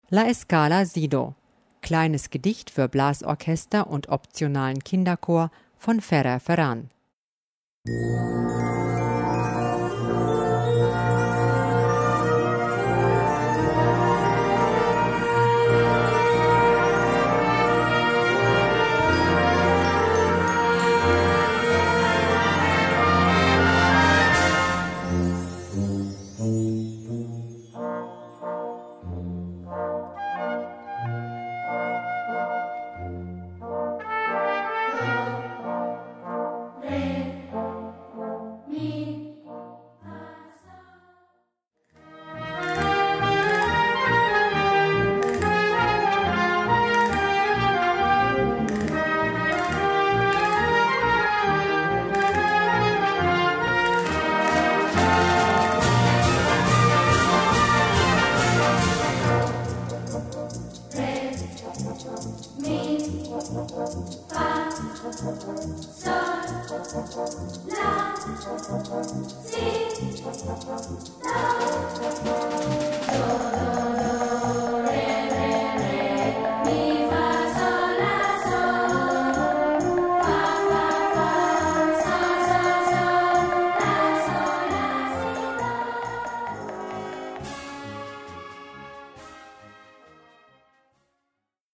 Gattung: Gedicht für Blasorchester und Kinderchor ad lib.
Besetzung: Blasorchester